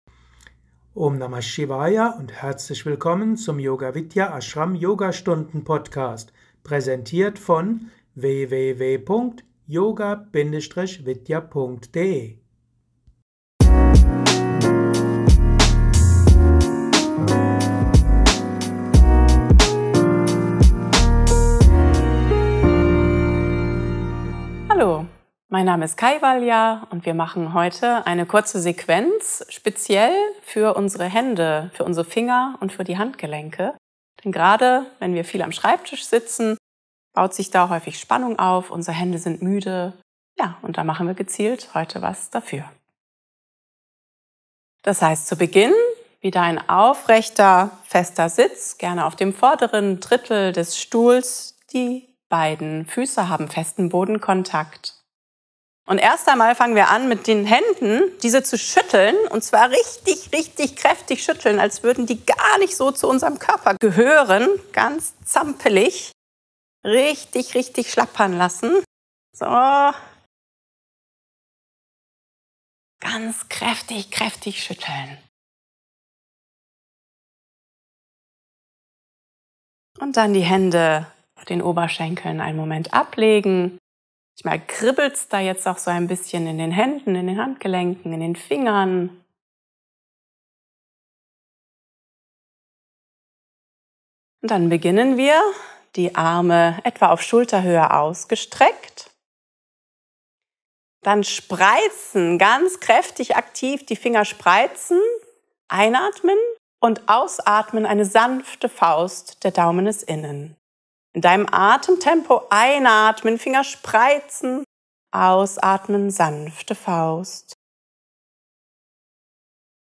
Mit Anleitung einer kurzen und effektiven Handmassage für verspannte Hände.